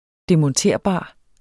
Udtale [ demʌnˈteɐ̯ˀˌbɑˀ ]